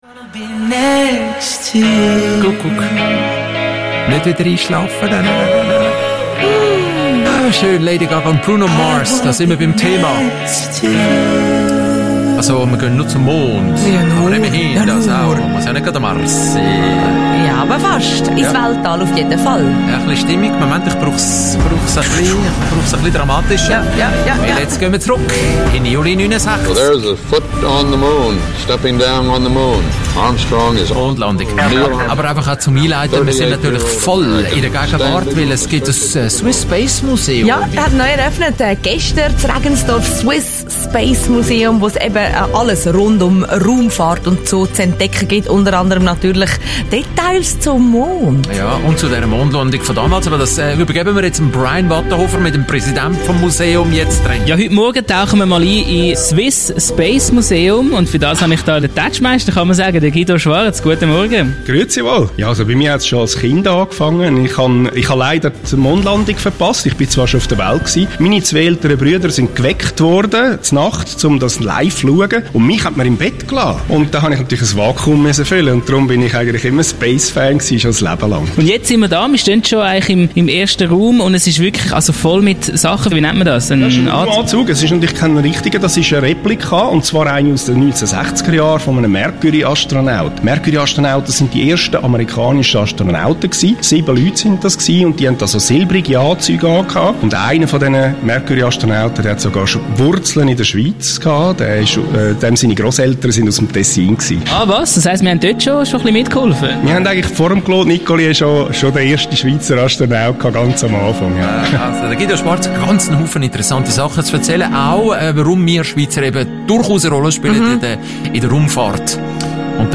Radiobeitrag Teil 2